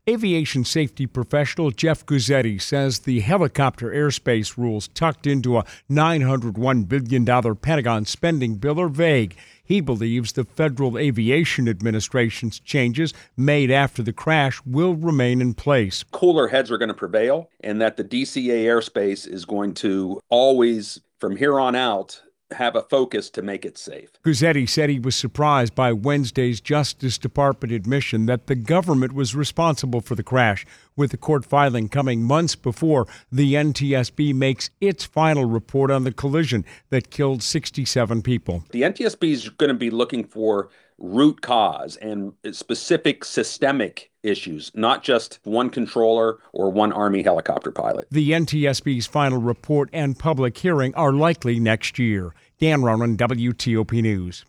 speaks with aviation safety professional